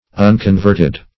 Unconverted \Un`con*vert"ed\, a.